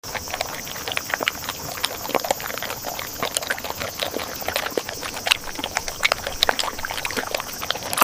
Watch this adorable squad all dig into the same bowl, munching and crunching in perfect ASMR harmony. Turn up the volume and let the satisfying sounds melt your stress away.